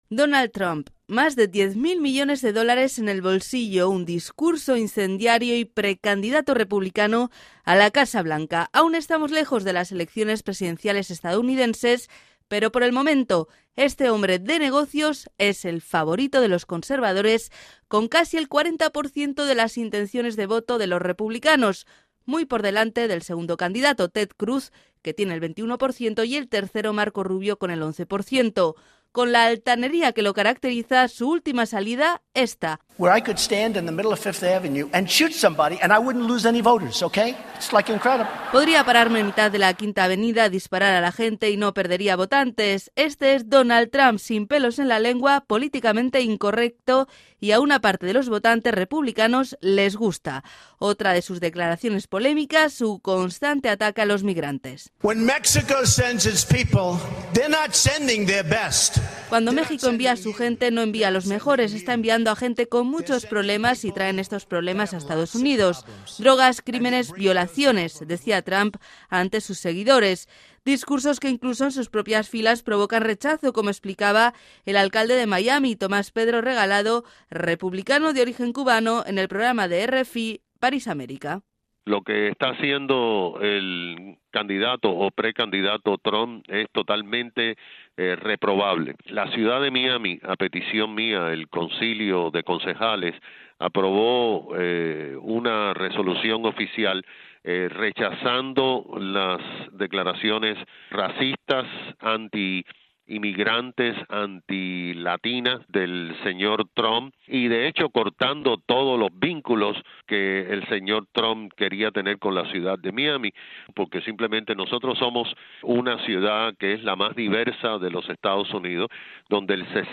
Entrevistados: el alcalde de Miami, Tomás Pedro Regalado